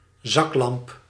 Ääntäminen
Synonyymit zaklantaarn pillamp pillicht Ääntäminen Tuntematon aksentti: IPA: /ˈzɑklɑmp/ Haettu sana löytyi näillä lähdekielillä: hollanti Käännös Ääninäyte 1. lanterna {f} 2. fax {f} Suku: f .